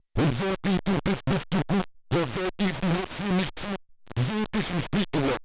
Wenn ich da bei meinem Neve mache, dann kommt da nur noch üble Verzerrung raus. Mal abgesehen davon, dass es nicht 80 dB sind, sondern -80 dB: das ist Mic Preamp maximal Verstärkung.